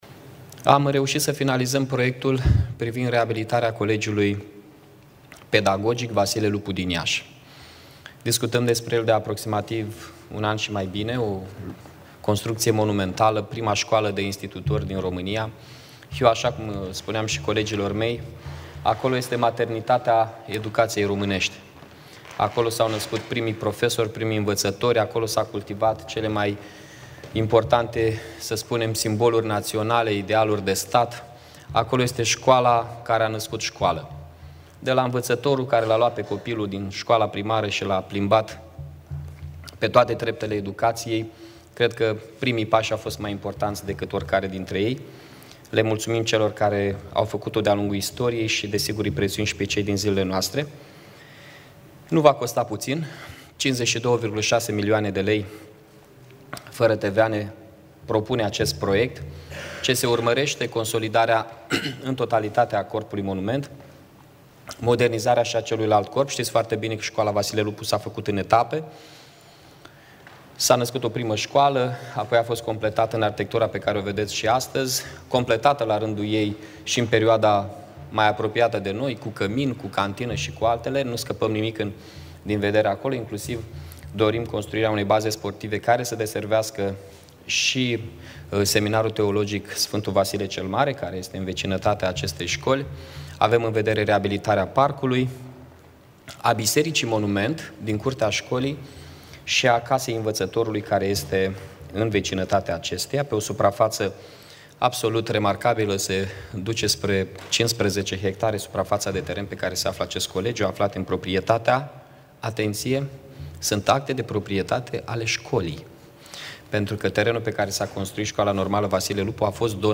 Declarațiile primarului municipiului Iași